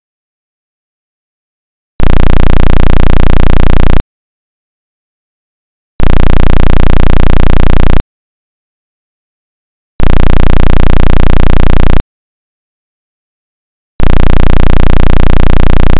15hz_loop.wav